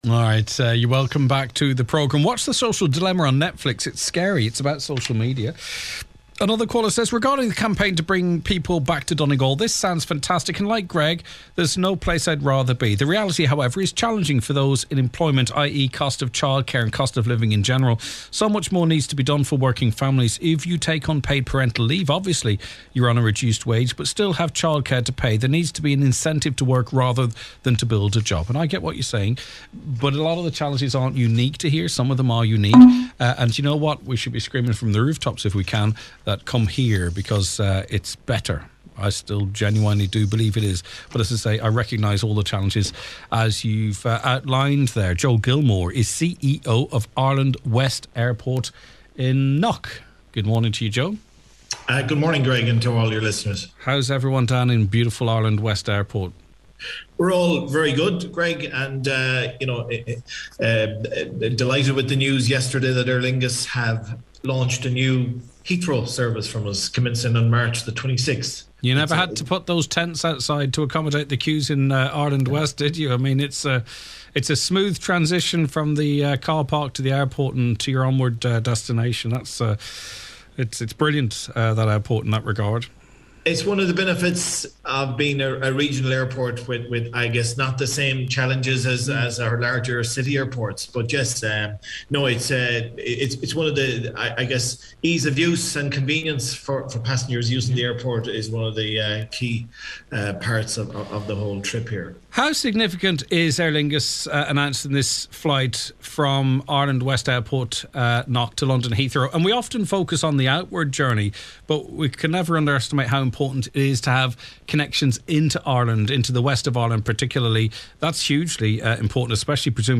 Weekday’s 9am to 12noon Magazine type mid morning chat show providing a forum for listeners to express their concerns on the issues of the day.